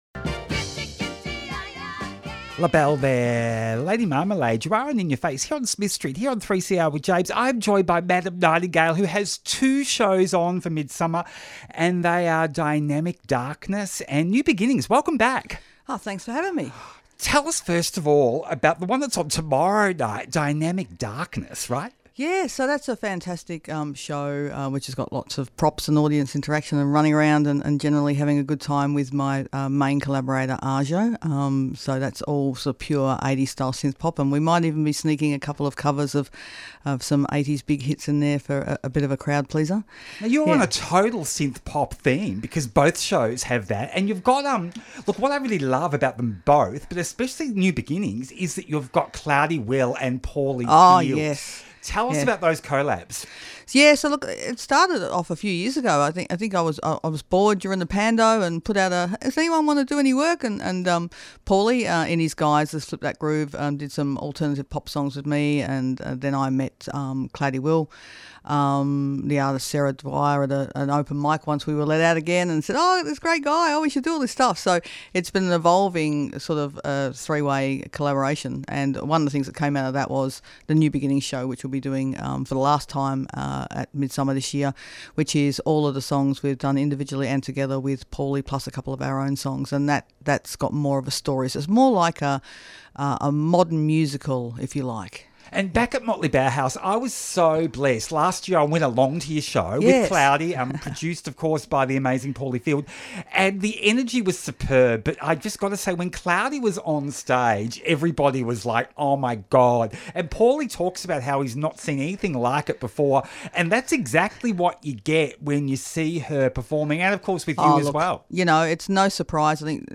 Documentary with queer artists and musicians during Covid.
Includes songs by the musicians. Interviews recorded remotely, May to October 2020. 3CR broadcasts from the stolen lands of the Kulin Nation.